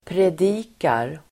Uttal: [pred'i:kar]